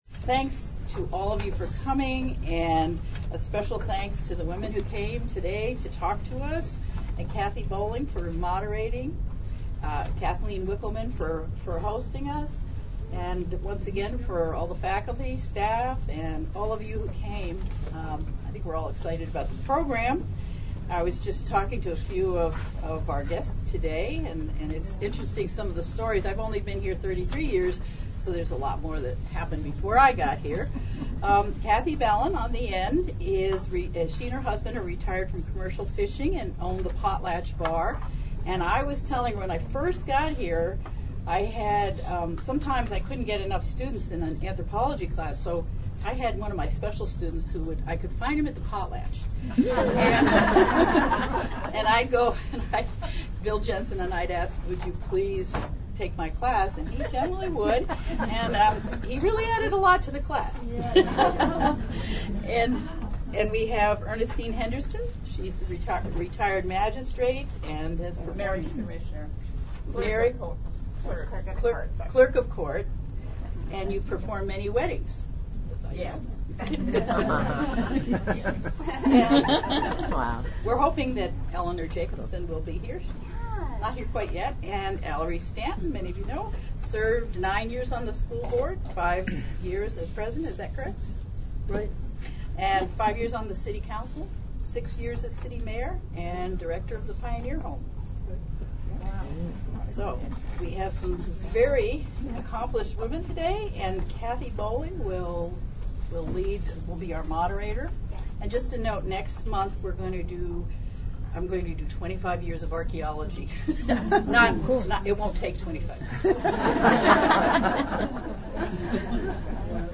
A packed audience filled the university library for the talk, and some in the audience joined the conversation.